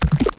Amiga 8-bit Sampled Voice
splash.mp3